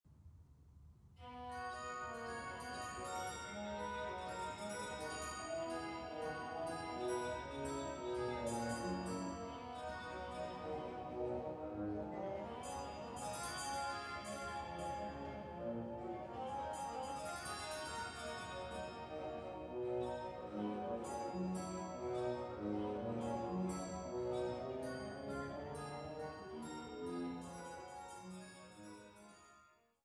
Praeludium a-Moll